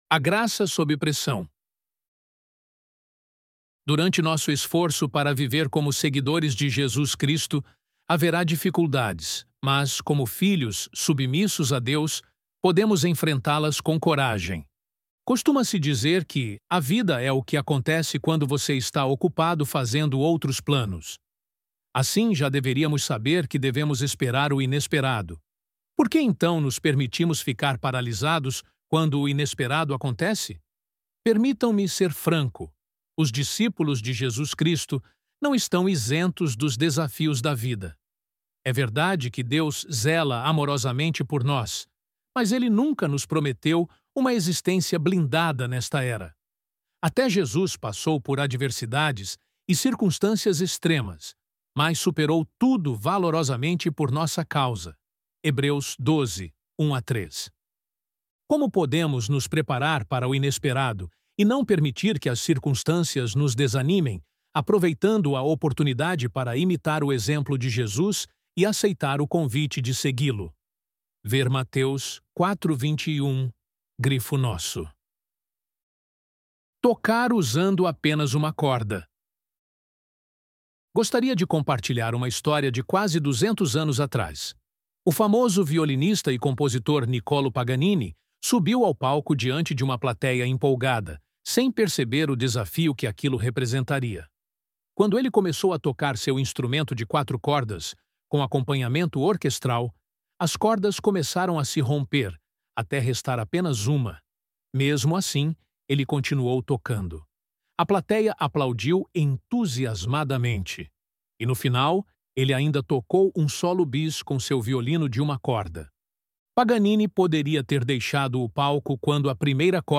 ElevenLabs_A_Graça_Sob_Pressão.mp3